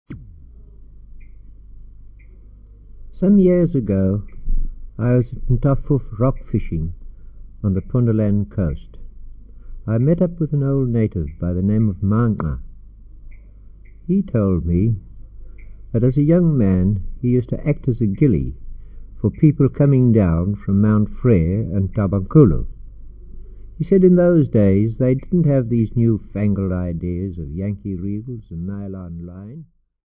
field recordings
Transkei story.
30ips pancake reel